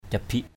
/ca-biʔ/ 1.
cabik.mp3